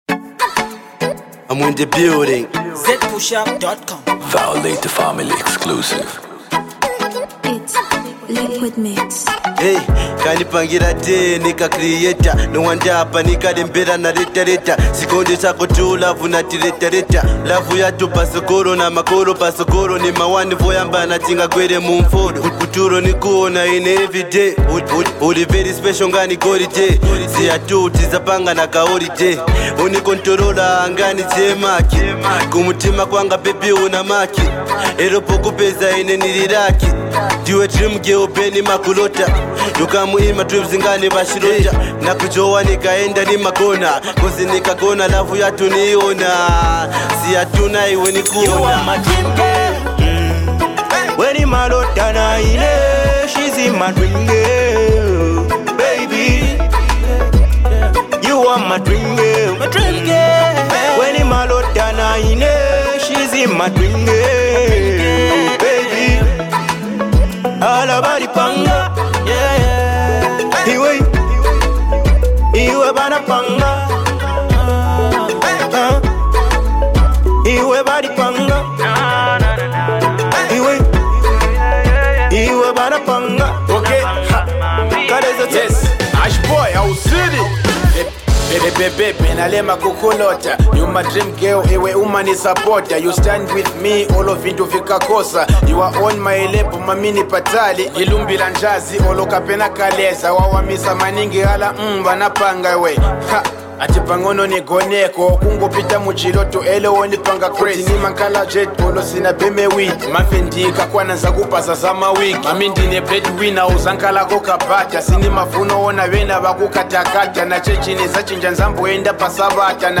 A dope critical love joint